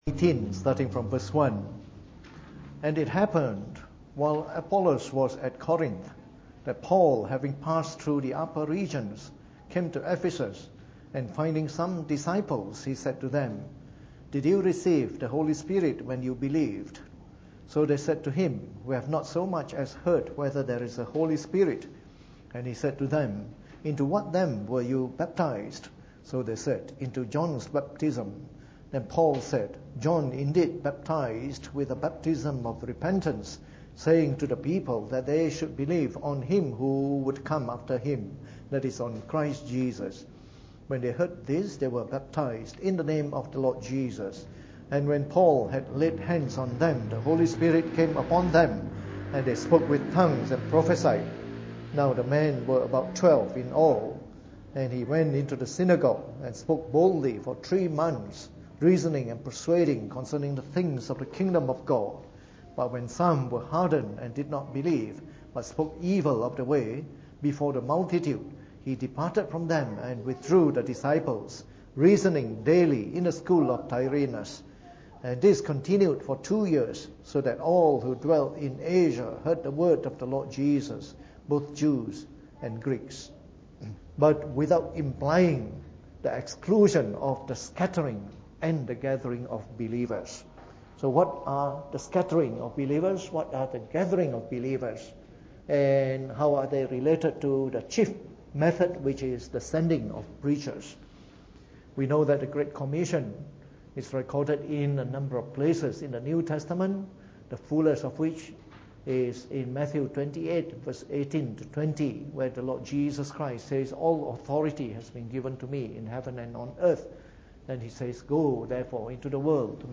Preached on the 12th of July 2017 during the Bible Study, from our series on Church Planting Today.